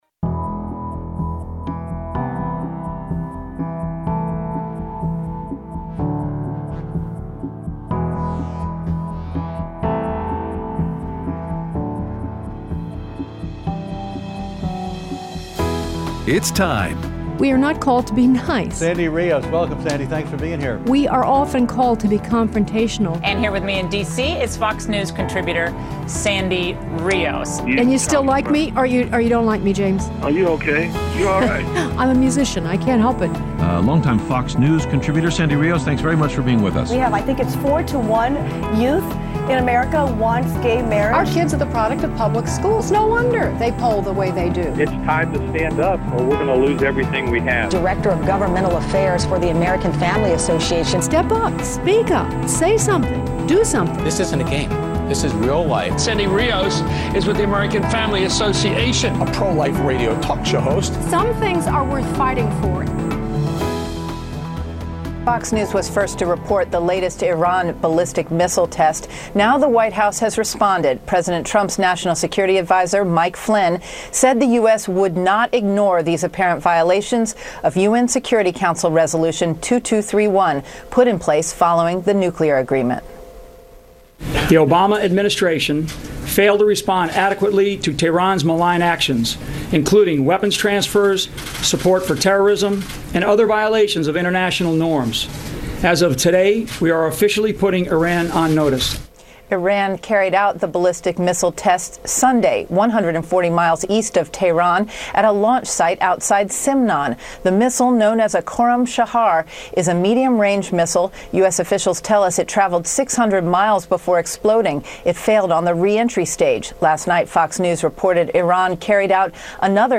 Aired Thursday 2/2/17 on AFR 7:05AM - 8:00AM CST